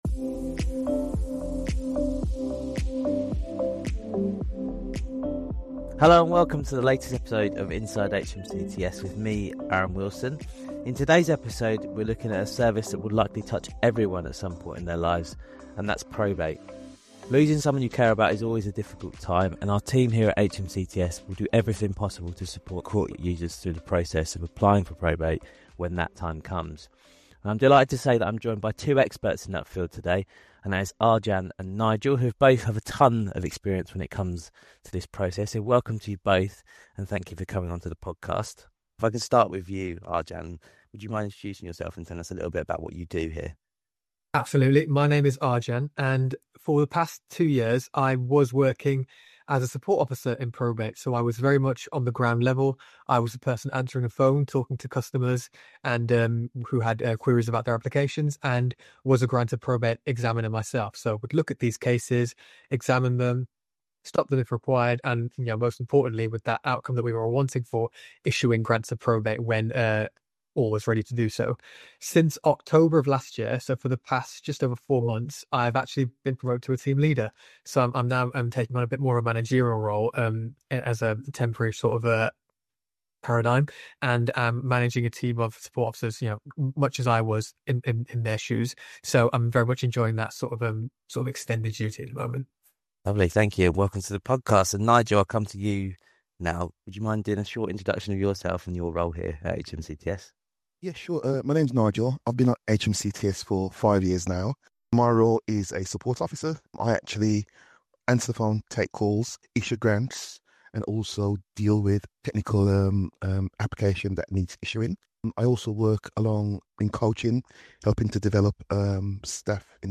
In this episode, our colleagues share expert advice on how to make the probate process easier and less stressful.